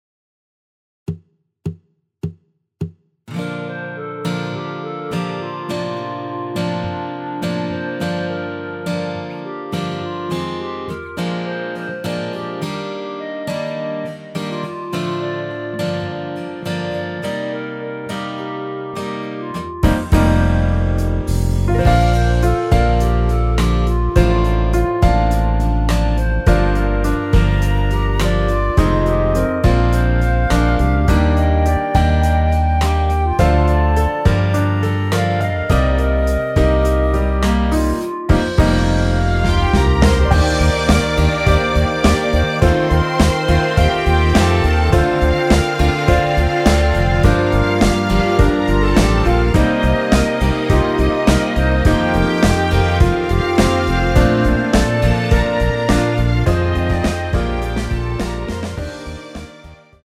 원키 멜로디 포함된 MR입니다.
Bb
멜로디 MR이라고 합니다.
앞부분30초, 뒷부분30초씩 편집해서 올려 드리고 있습니다.
중간에 음이 끈어지고 다시 나오는 이유는